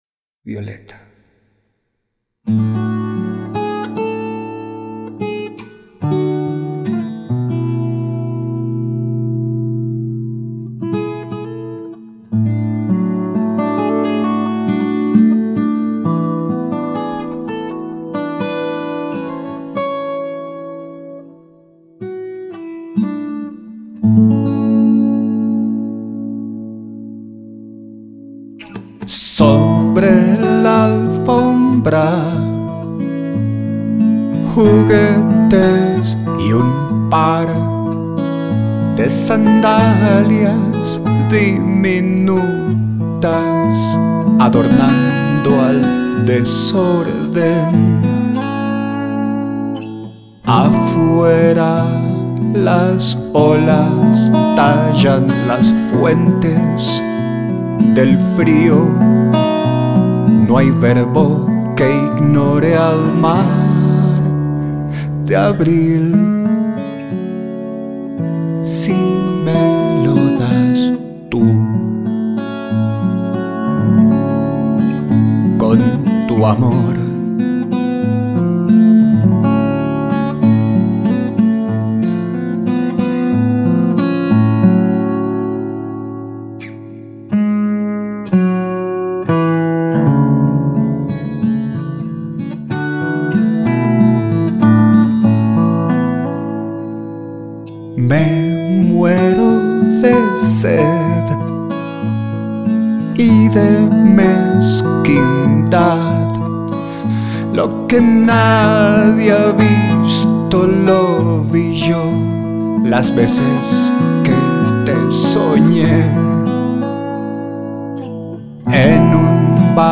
guitarra electroacústica, voz.